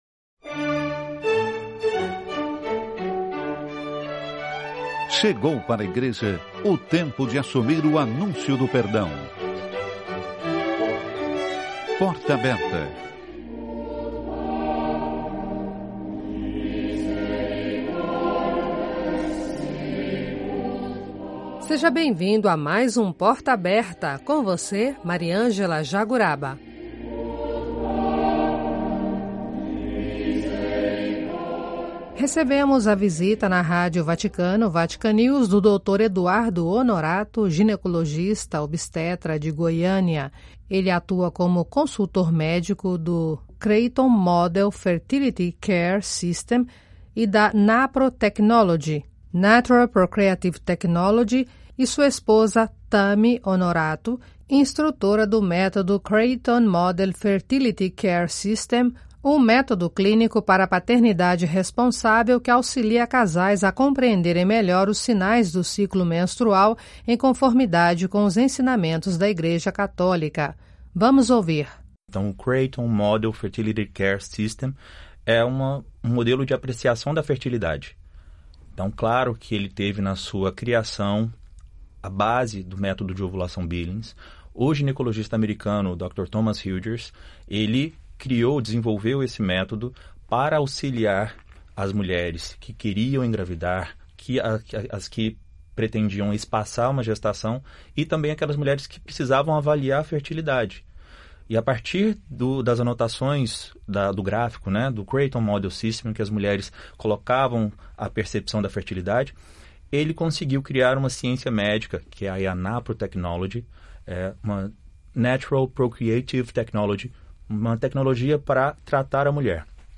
Noticiário (12:00 CET).